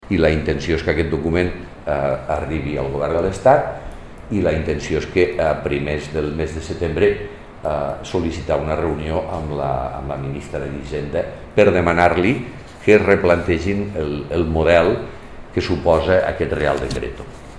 Tall de veu de l'alcalde, Miquel Pueyo, sobre l'adhesió de Lleida al comunicat de diversos ajuntaments perquè l’Estat replantegi el decret de mesures financeres